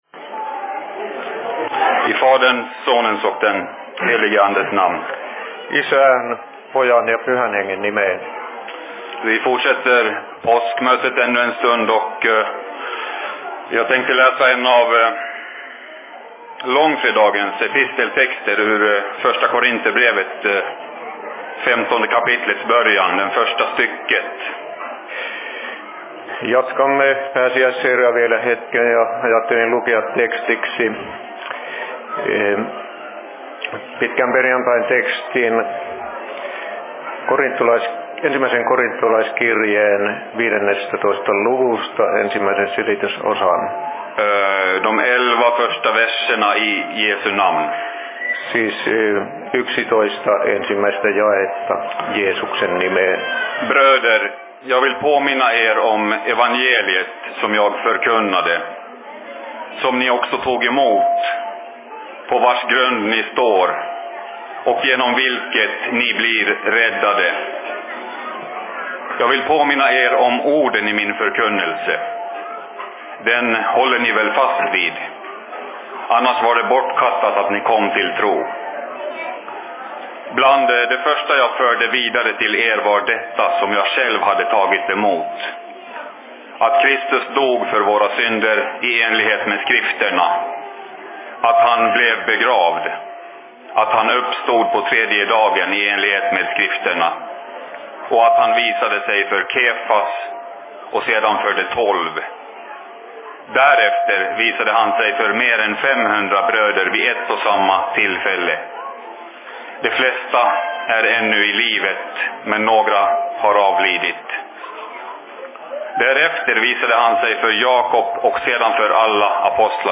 Påskmötet/Se Fi Predikan I Dalarnas Fridsförening 05.04.2015
Paikka: SFC Dalarna
Simultaanitulkattu